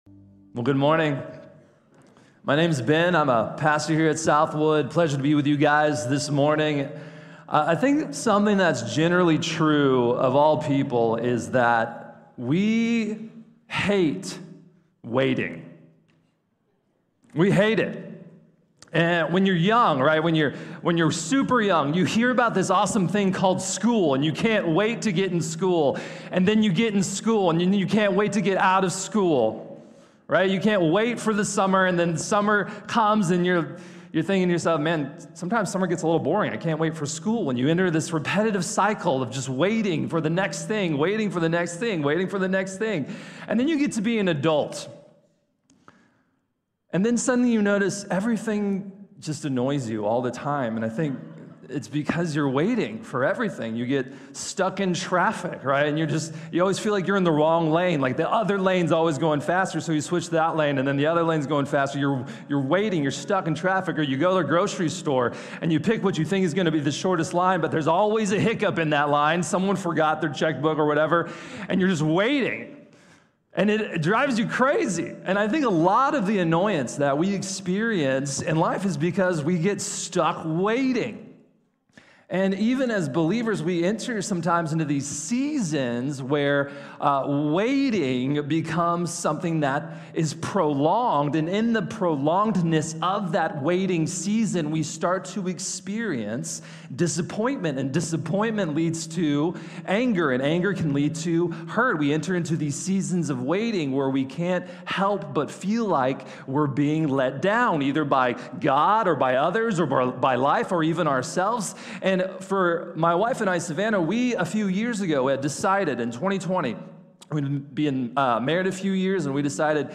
La oración desesperada de Ana | Sermón | Iglesia Bíblica de la Gracia